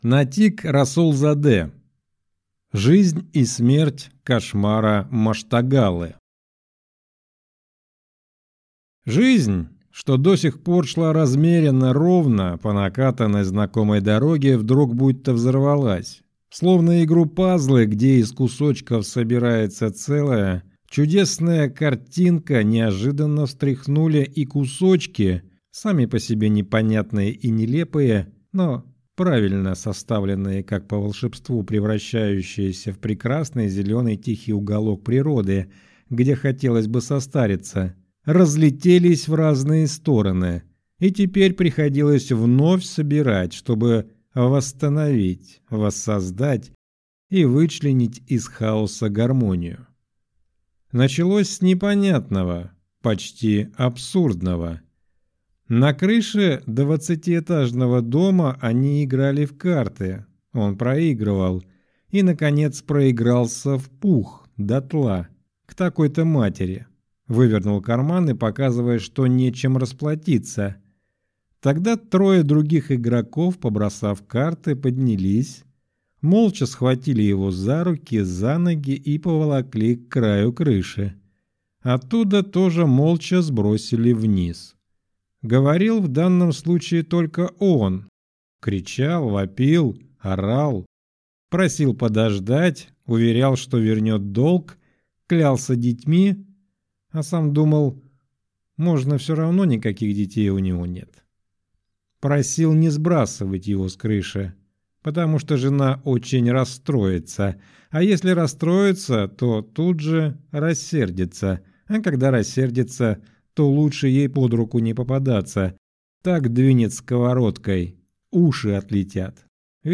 Аудиокнига Жизнь и смерть Кашмара Маштагаллы | Библиотека аудиокниг